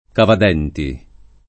cavadenti [ kavad $ nti ] s. m.